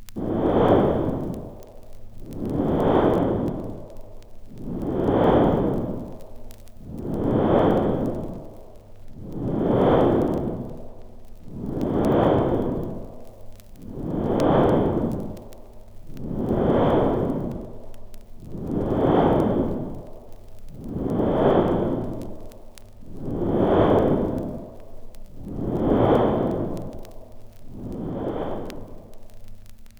• the pendulum - horror stereo vinyl rip.wav
the_pendulum_-_horror_stereo_vinyl_rip_5Hw.wav